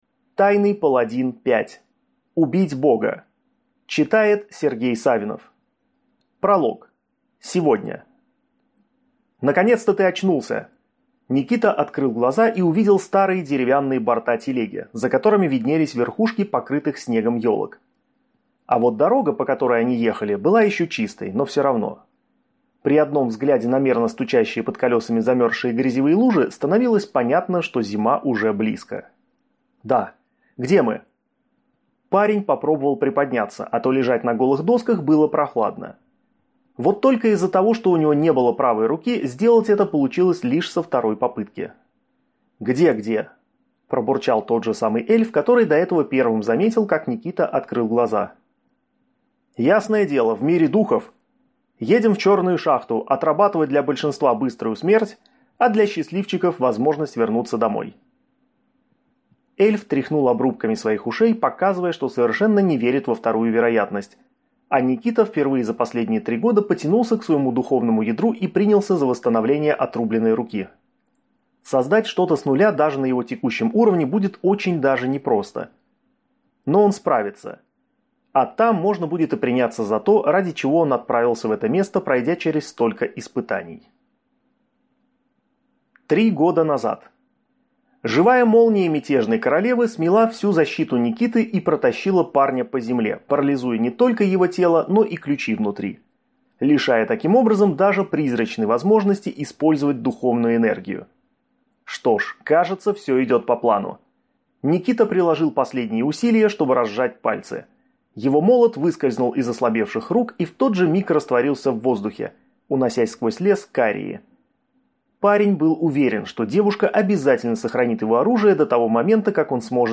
Аудиокнига Тайный паладин 5. Убить бога | Библиотека аудиокниг